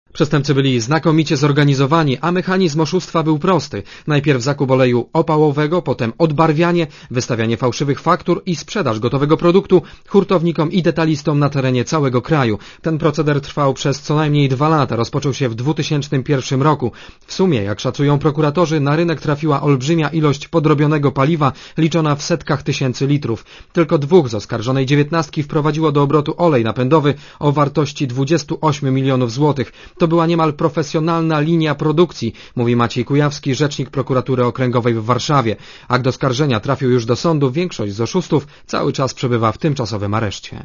Źródło zdjęć: © RadioZet 28.12.2004 13:09 ZAPISZ UDOSTĘPNIJ SKOMENTUJ Relacja reportera Radia ZET